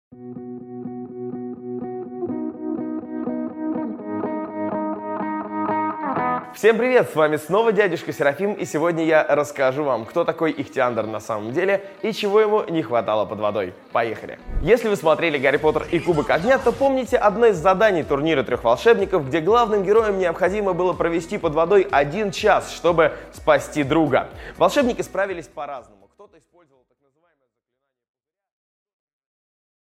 Аудиокнига Дыхание под водой | Библиотека аудиокниг